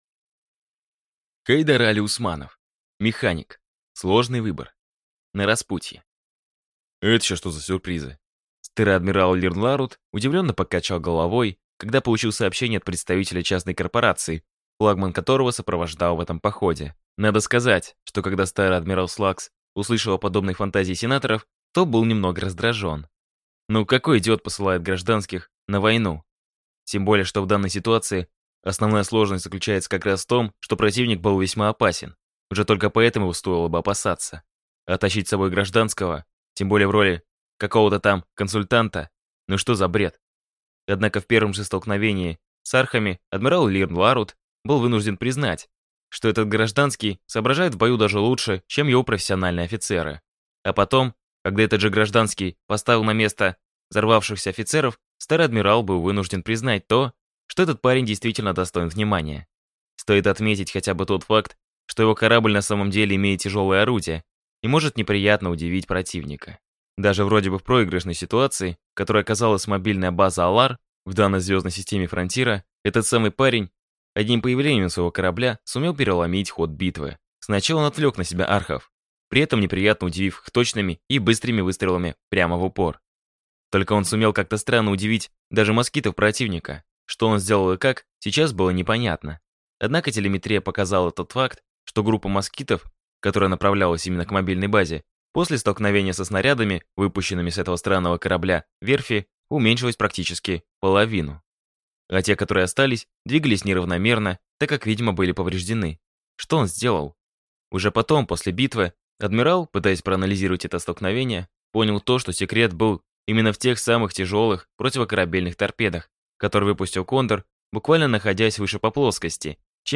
Аудиокнига Механик. Сложный выбор | Библиотека аудиокниг